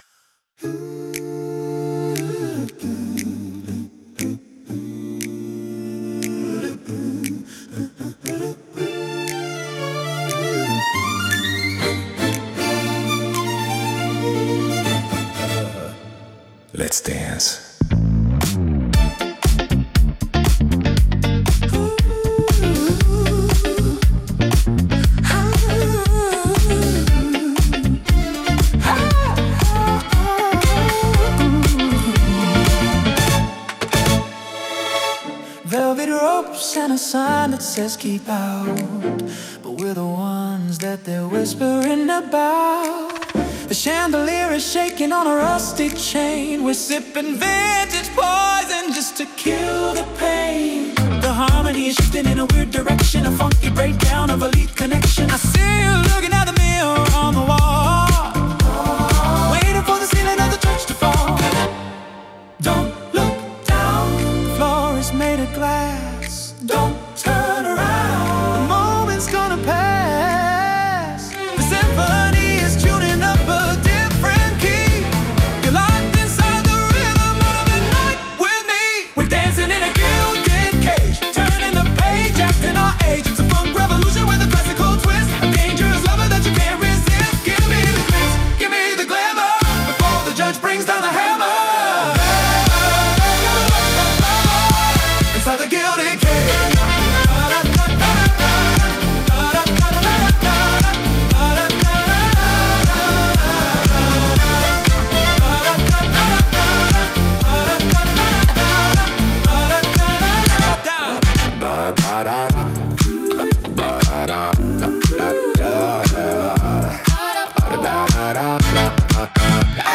polished retro-futurism